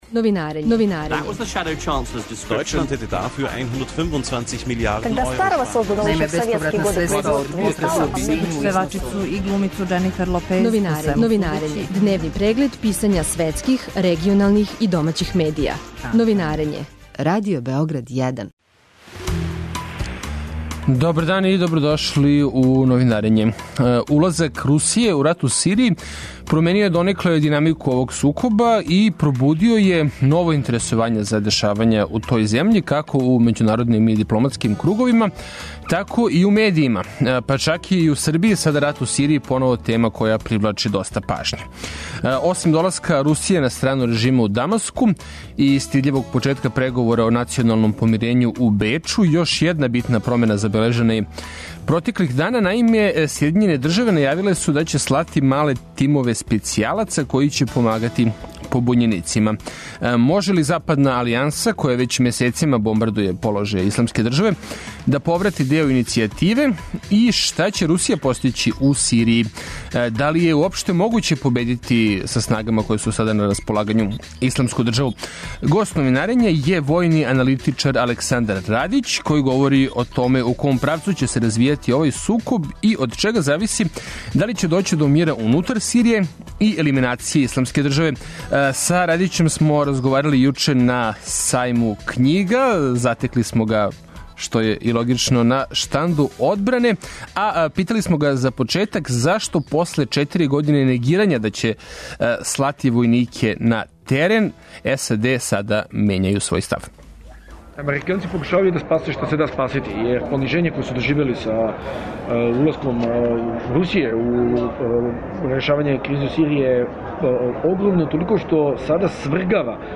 Гост Новинарења је војни аналитичар